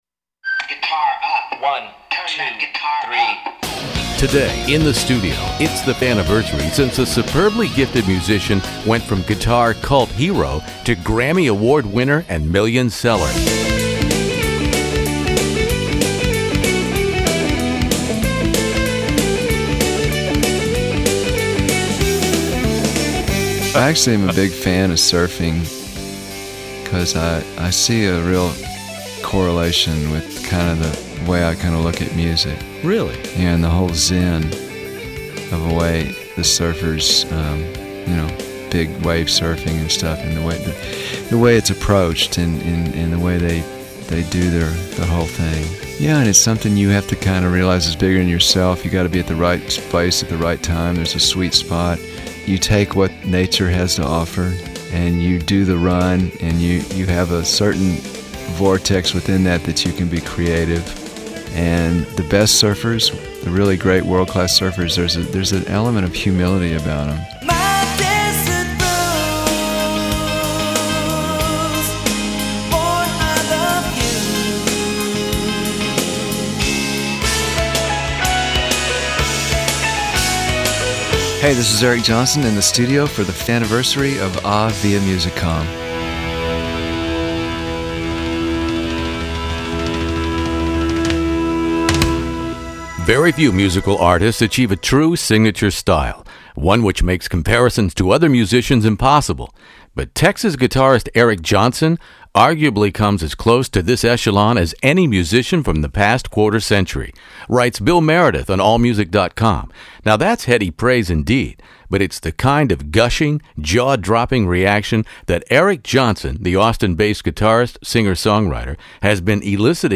classic rock interview